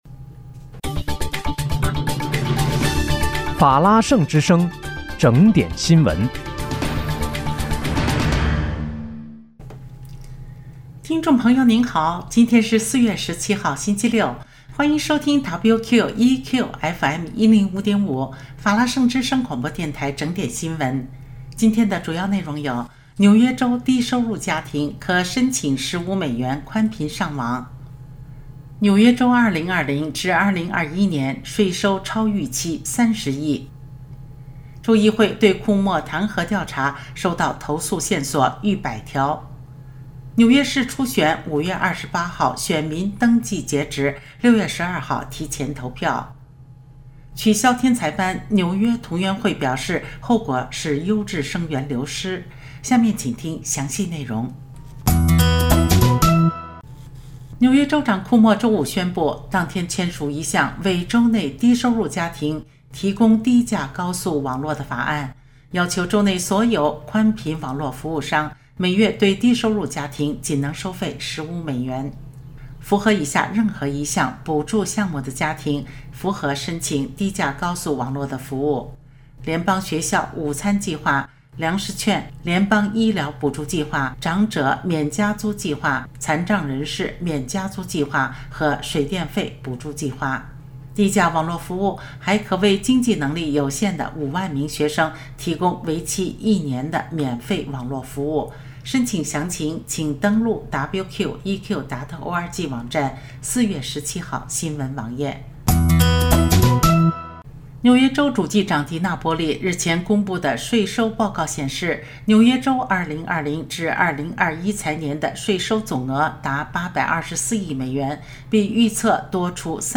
4月17日（星期六）纽约整点新闻